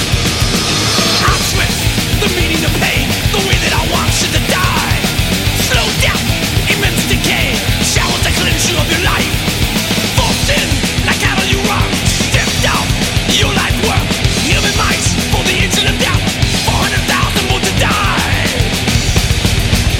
Музыка » Rock » Rock